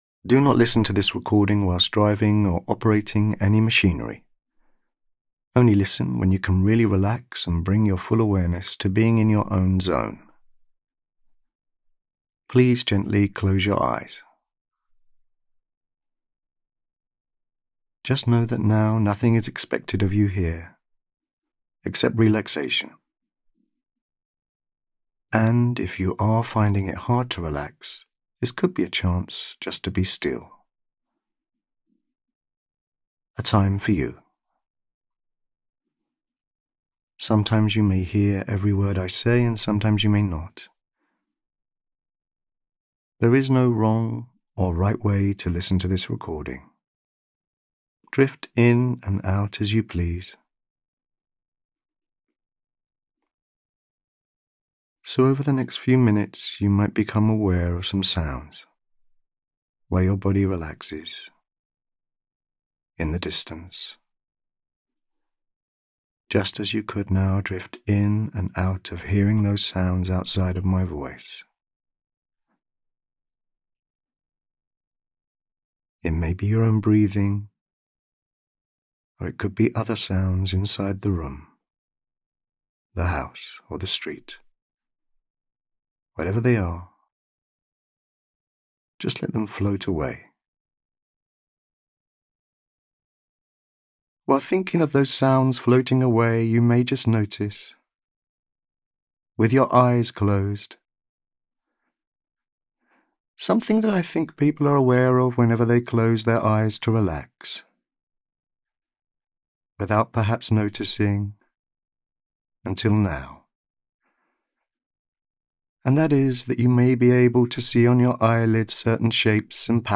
Click the download button to unlock instant access to a soothing relaxation audio designed to help you release stress, find your inner calm, and create a sense of peace in your daily life.